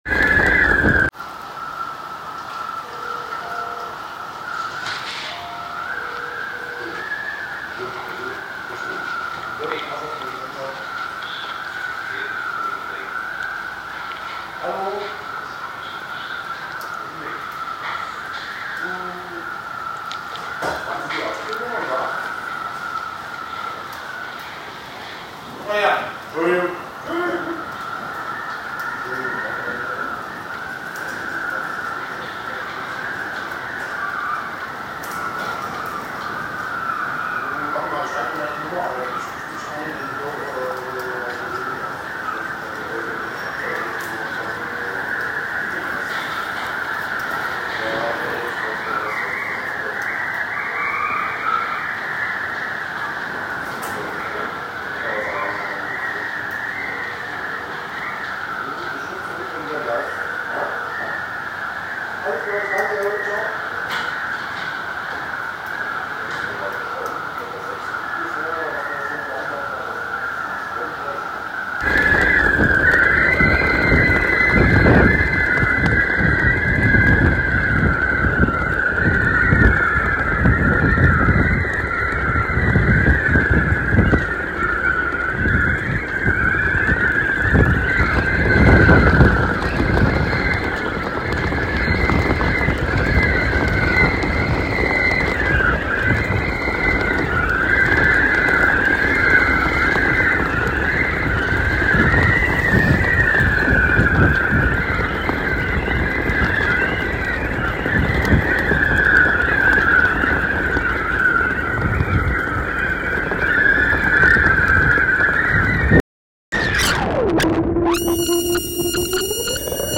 It’s experimental.
It ranges from field recordings to noise to 4-track dual mono sounds to whatever comes to mind.
= ɥʇɹnʍ = is part (I) field recording, part (II) different.